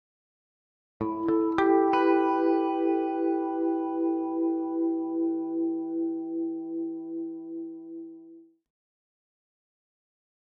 Electric Guitar Harmonic Arpeggio With Flange 1